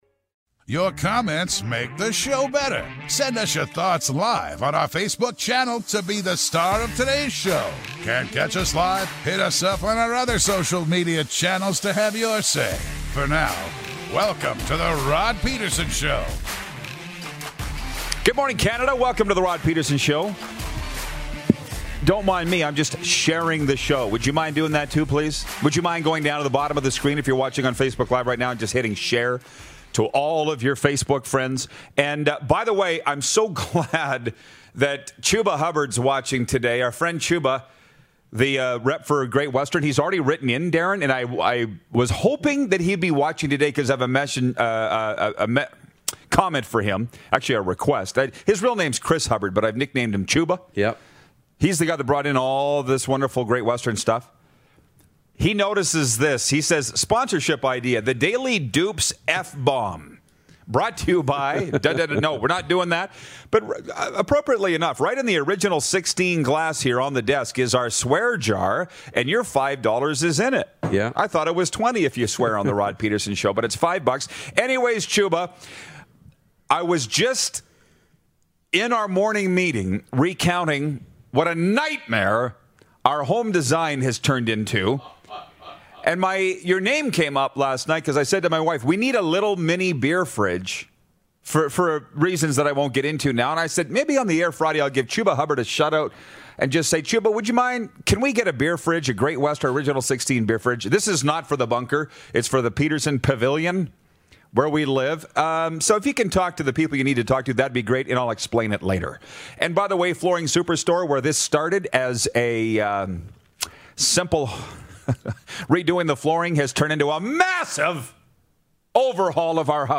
is back in-studio in Hour 1!